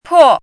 chinese-voice - 汉字语音库
po4.mp3